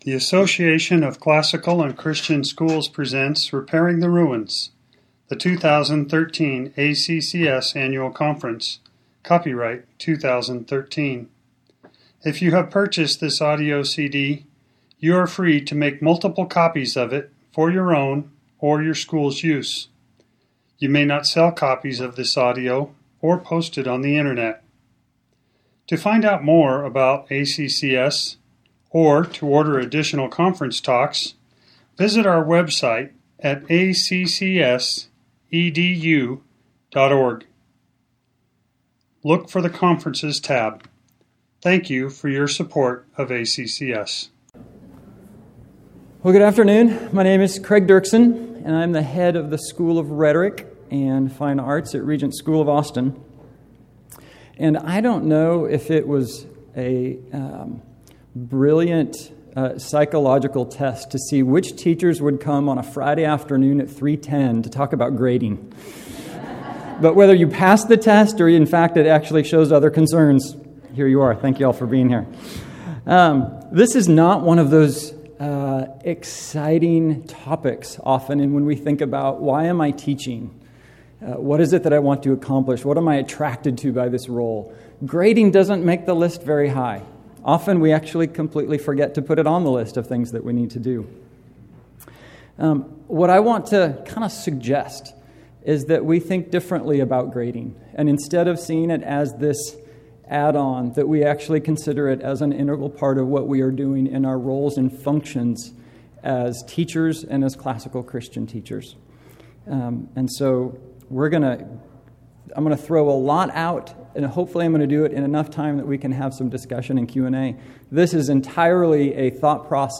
2013 Workshop Talk | 1:08:27 | All Grade Levels, General Classroom